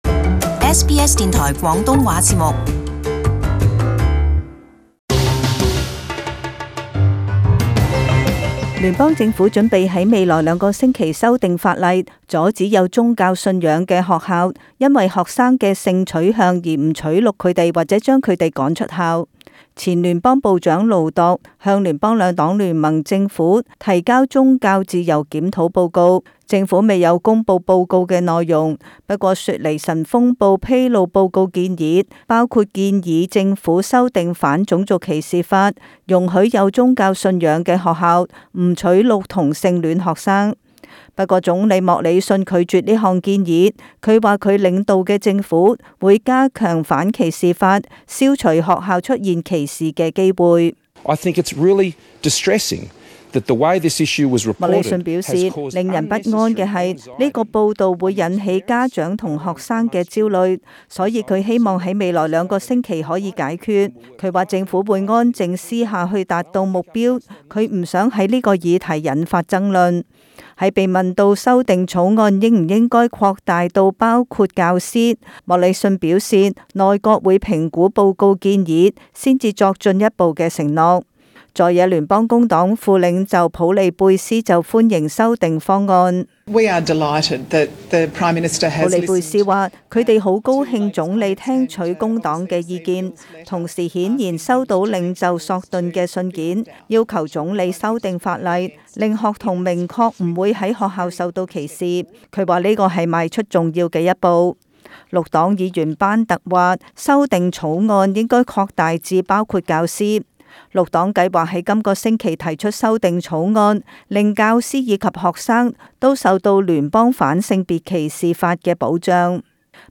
【時事報導】聯邦將立法保障不同性取向學生